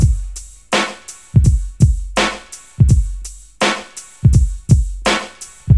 • 83 Bpm 1990s Reggae Drum Loop G Key.wav
Free drum groove - kick tuned to the G note. Loudest frequency: 1334Hz